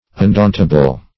Search Result for " undauntable" : The Collaborative International Dictionary of English v.0.48: Undauntable \Un*daunt"a*ble\ ([u^]n*d[aum]nt"[.a]*b'l), a. Incapable of being daunted; intrepid; fearless; indomitable.